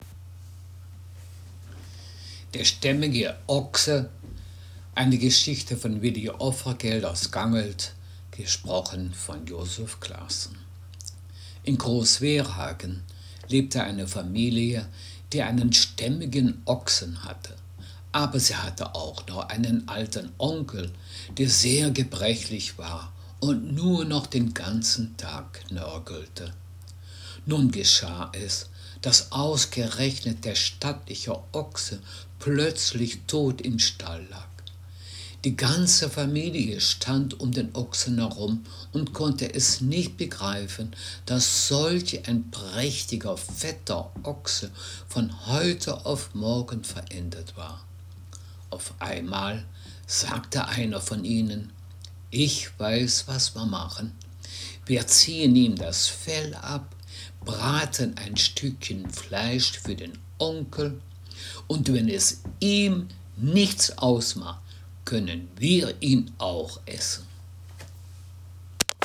Geschichte